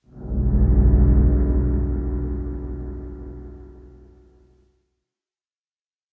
cave7.ogg